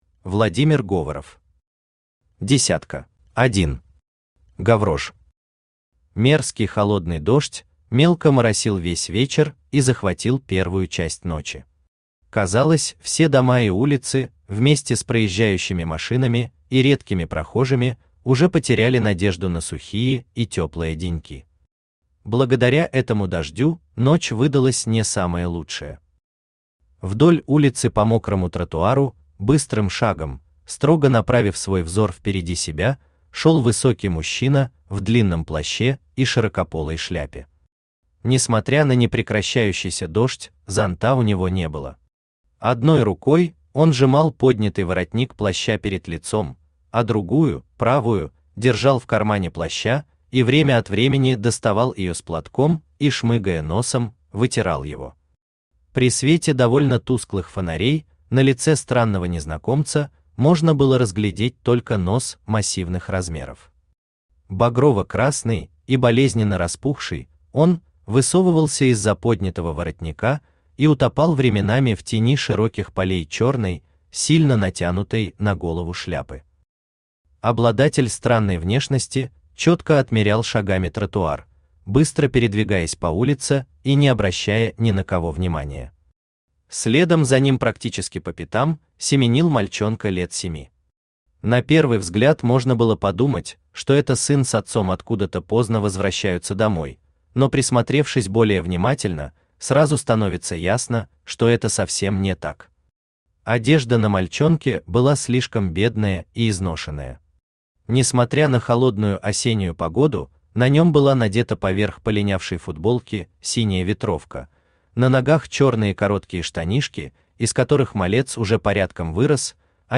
Aудиокнига Десятка Автор Владимир Говоров Читает аудиокнигу Авточтец ЛитРес.